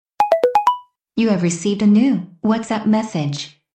Ringtones Category: Message tones